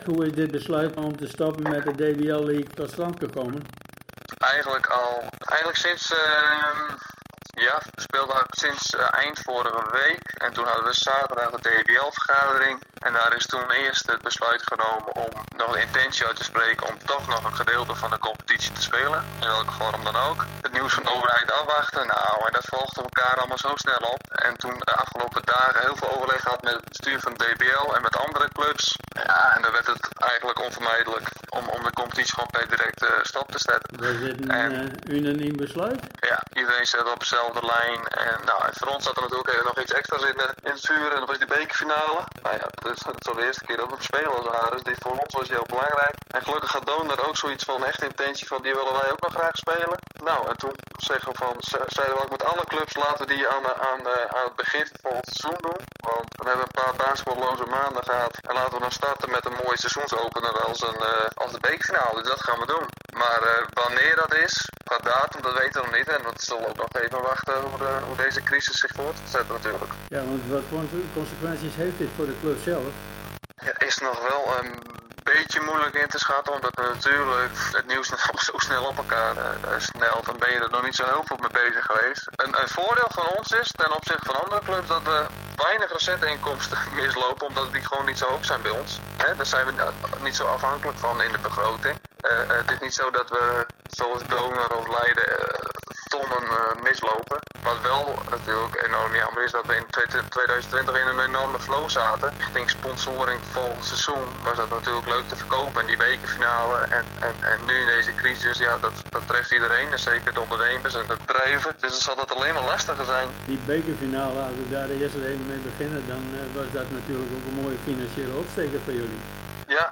(excuses voor de slechte geluid kwaliteit)